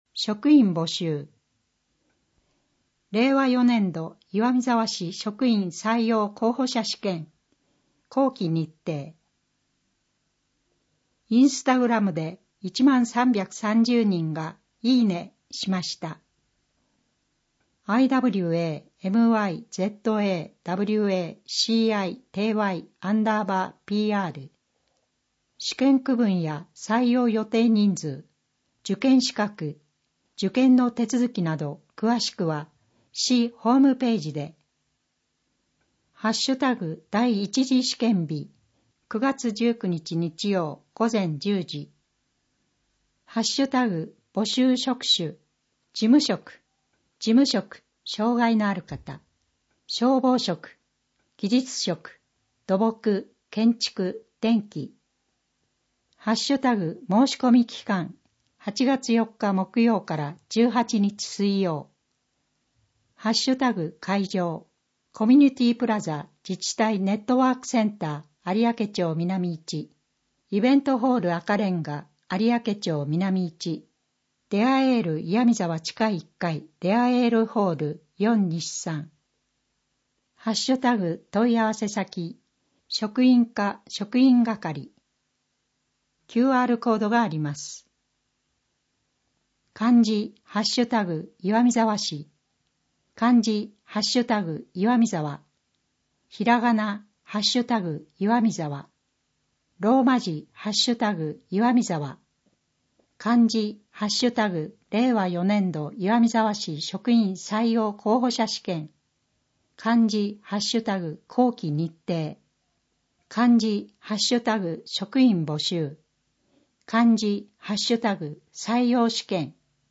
声の広報MP3版は、岩見沢さつきの会にご協力をいただき、録音しているものです。
声の広報（MP3）